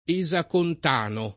esacontano [ e @ akont # no ] s. m. (chim.)